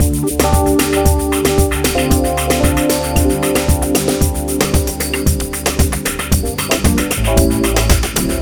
Ala Brzl 2 Full Mix 3a-D.wav